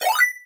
Звуки для игр
Звук успешного сохранения